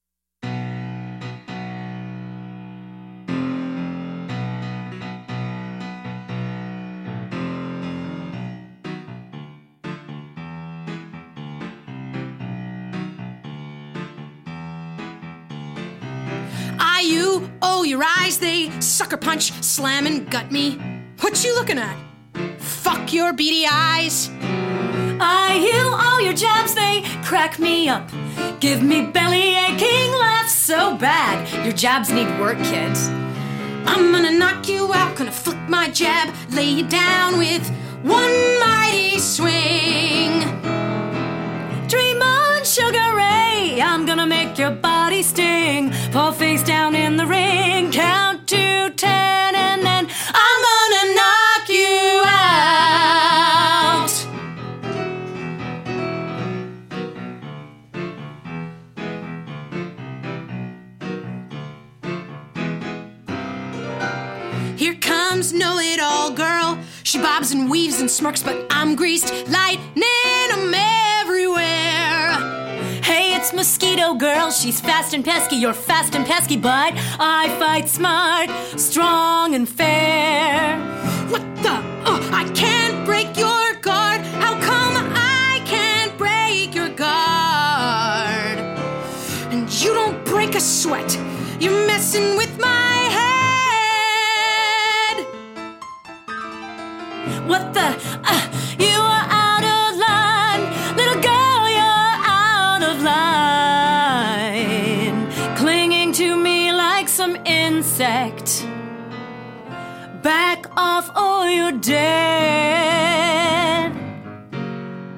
a boxing duet between young Polly and Raylene.
Polly & the Penthouse rehearsal for In Tune workshop presentation 2019, Penthouse Nightclub.